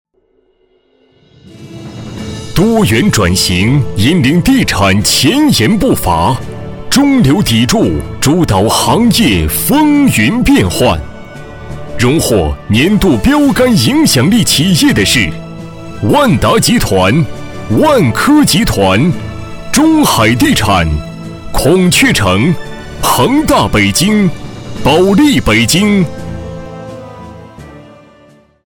A男139号
【颁奖】气势2017年宣传片